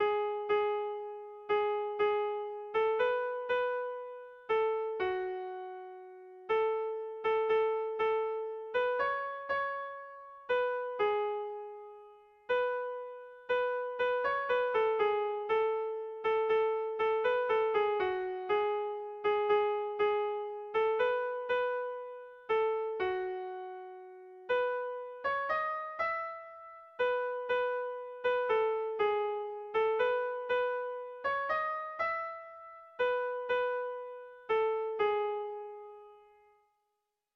Gabonetakoa
ABDE